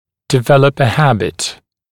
[dɪ’veləp ə ‘hæbɪt][ди’вэлэп э ‘хэбит]приобрести привычку